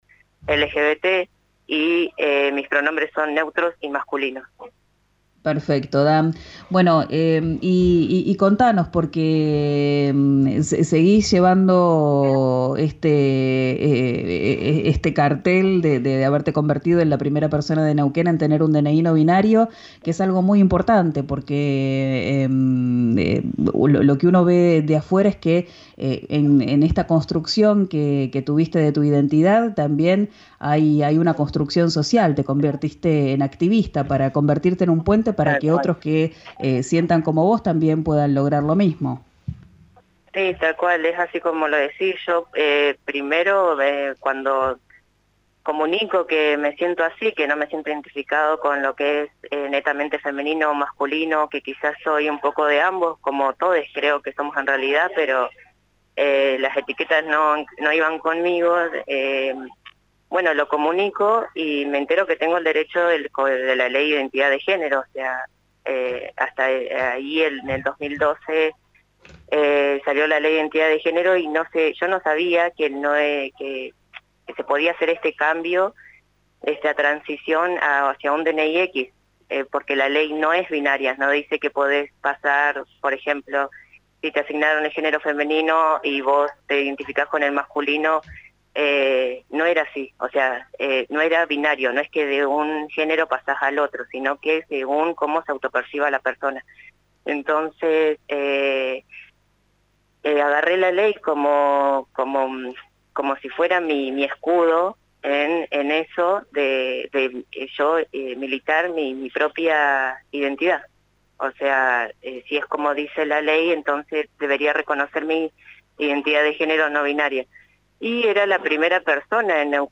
Escuchá la entrevista completa en RÍO NEGRO RADIO.